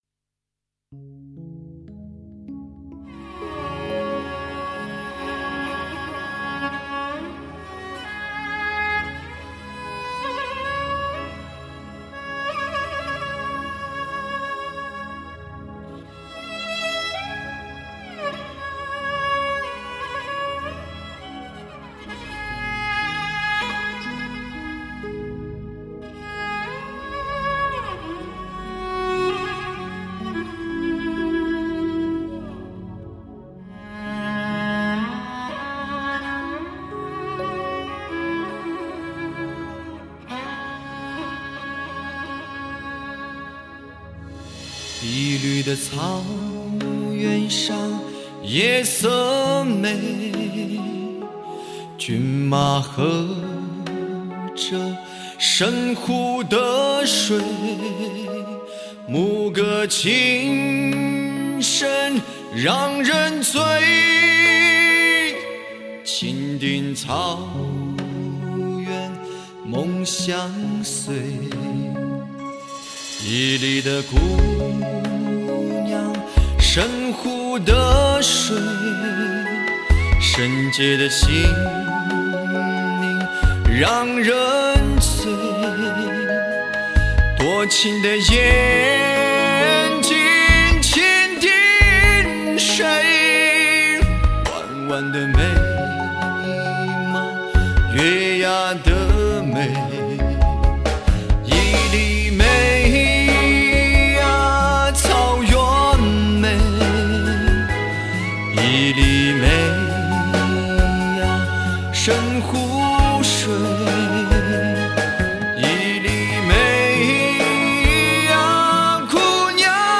国际化的顶尖制作水准，原生态的西部风情魅惑。